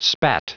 Prononciation du mot spat en anglais (fichier audio)
Prononciation du mot : spat